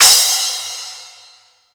• Crash Sound Clip E Key 07.wav
Royality free crash cymbal sound tuned to the E note. Loudest frequency: 6061Hz
crash-sound-clip-e-key-07-e1q.wav